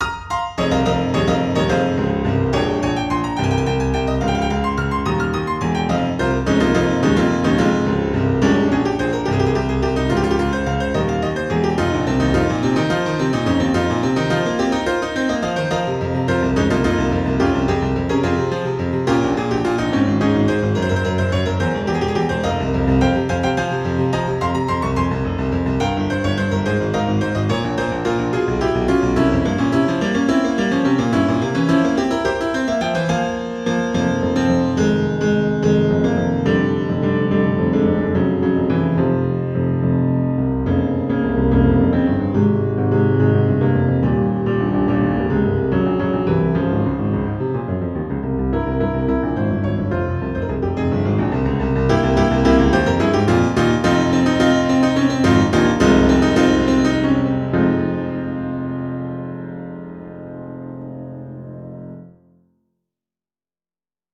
posted 3 years ago Musician This short piece little bit of piano-ing is my submission for the Furry Musicians' Express Yourself Contest this year.
The time signature changes quite a bit.
Music / Classical
The balance between tense chords and their resolution is awesome.
This is intricate and beautiful, with a lot of structure and easy listening but complex and sentimental. Distinct sections and energy, with very good use of different volumes and playing styles.